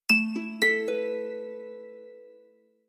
Красивый звук сообщения